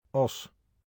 Oss (Dutch: [ɔs]